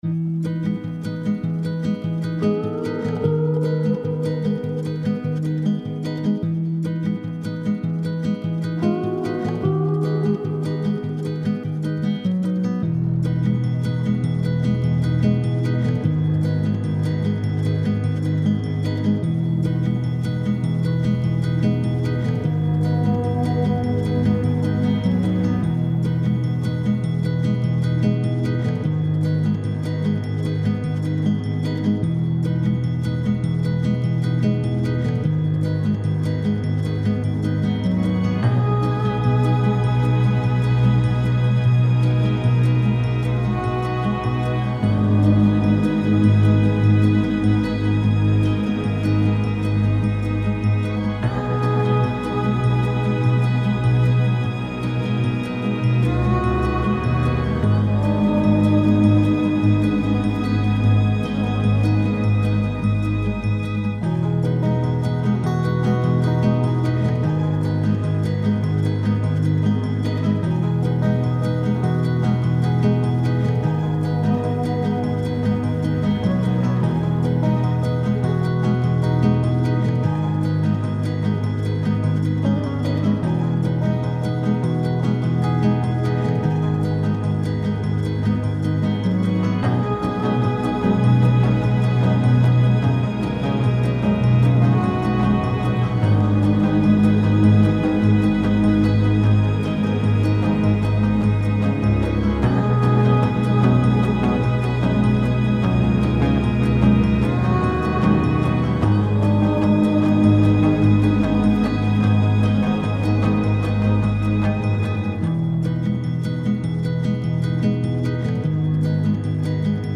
folk - calme - melodieux - melancolique - guitare